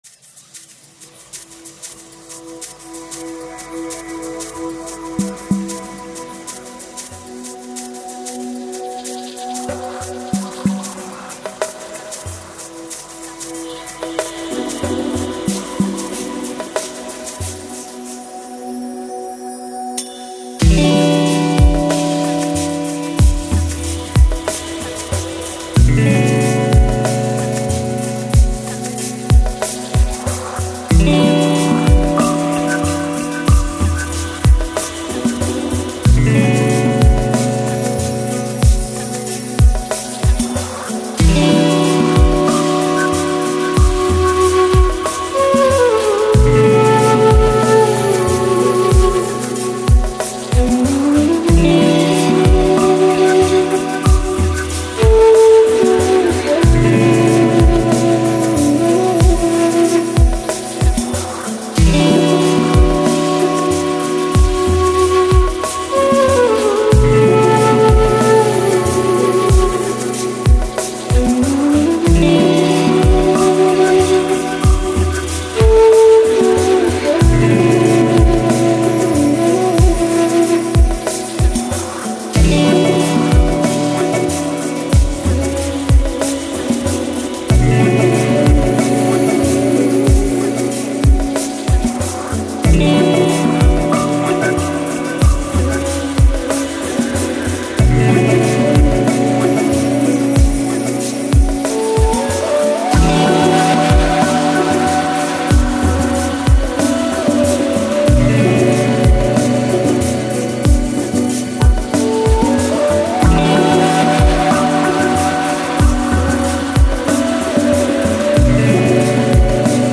Жанр: Lo-Fi, Lounge, Chillout